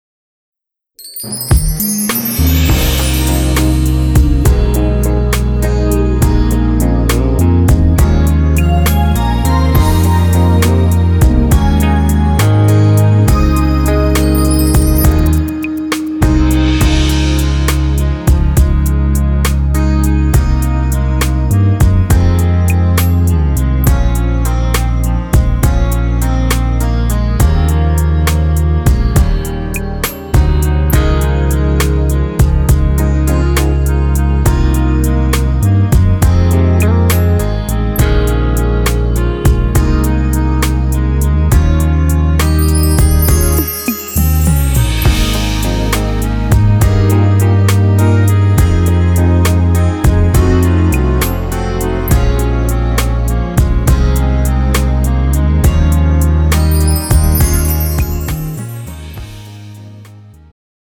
음정 남자-1키
장르 축가 구분 Pro MR